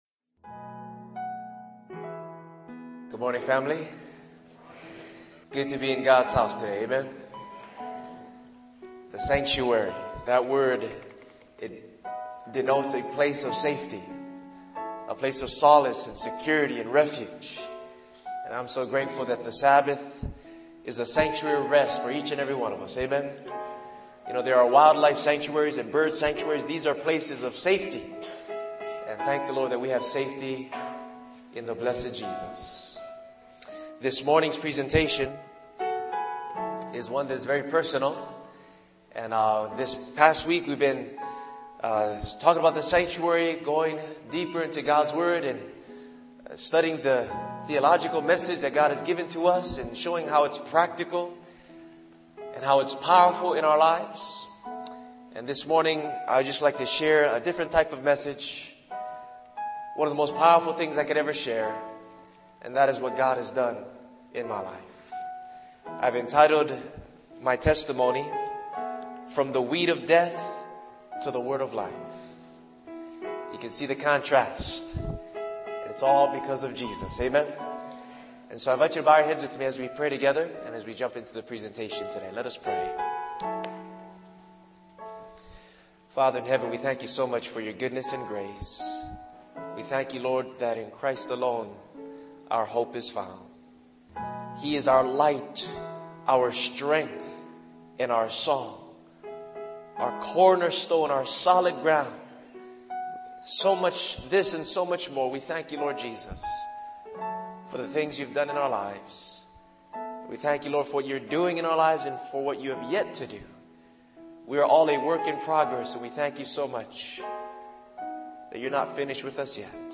during the West Coast Camp Meeting 2013